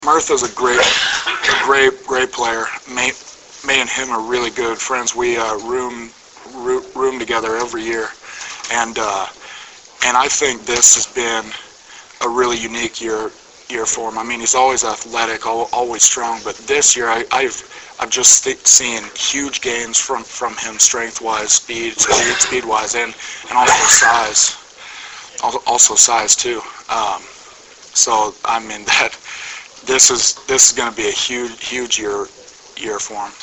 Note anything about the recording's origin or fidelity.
Preseason Press Conference